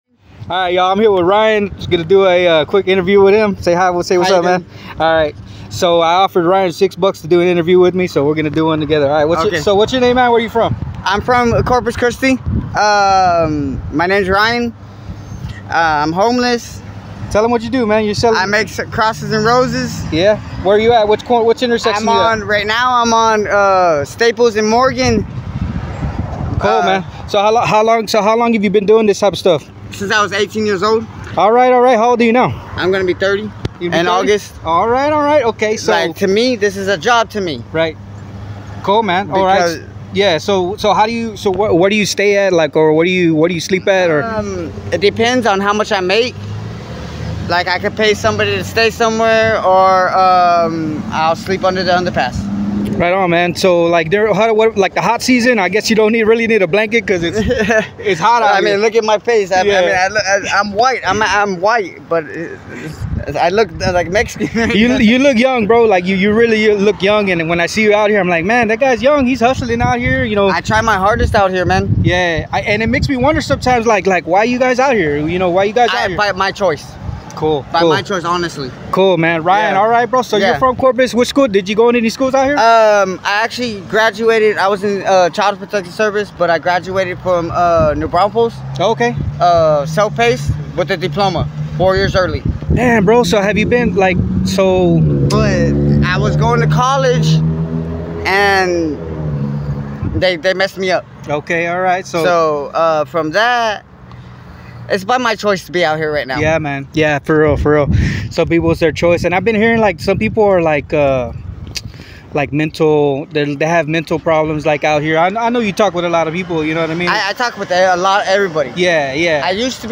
at the Staples and Morgan intersection in Corpus Christi, Texas.